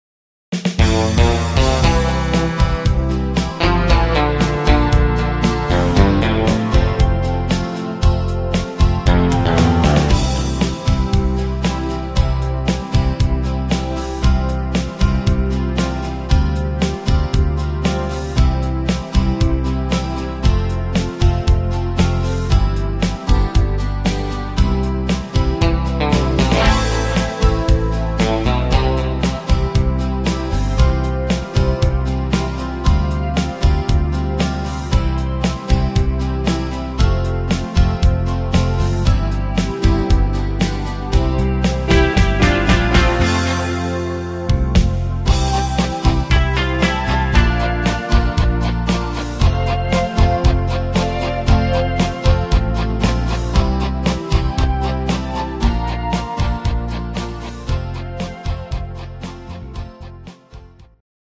Rhythmus  Medium Country
Art  Country, Deutsch